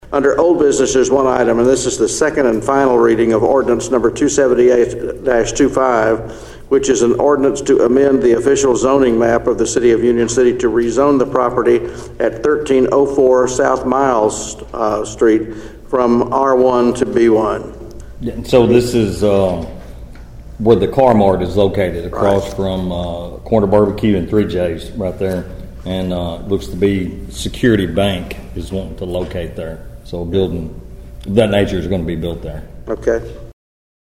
Union City Council members acted on a zoning amendment at Tuesday’s meeting at the Municipal Building.
Mayor Terry Hailey read the proposed amendment, with City Manager Johnny McTurner announcing the new business location.(AUDIO)